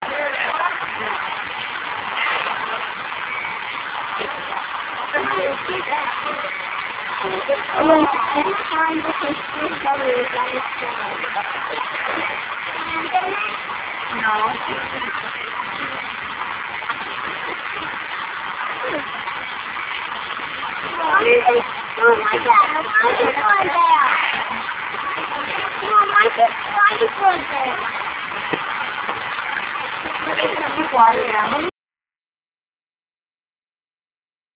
These are a series of short clips from a VHS video shot at the Erie Zoo, Erie Pennsylvania in the late 1980's The videos are in real video format.
Polar Bear